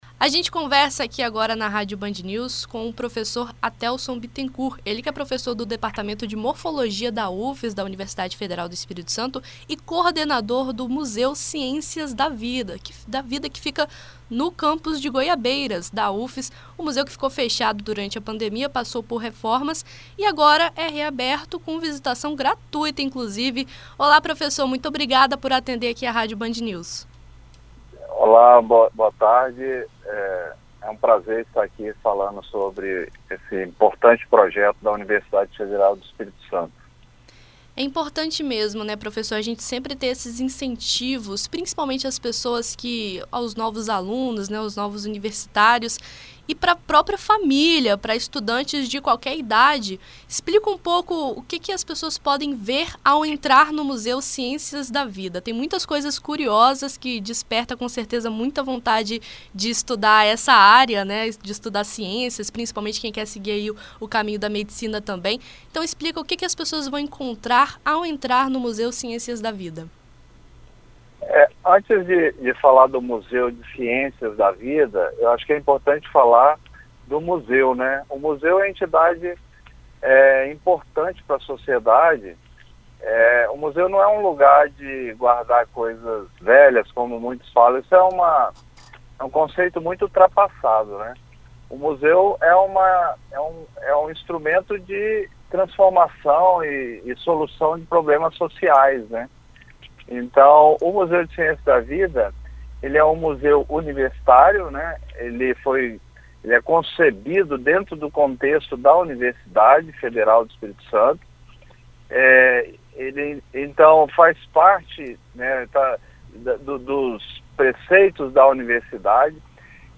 Em entrevista à BandNews FM Espírito Santo nesta quarta-feira (21)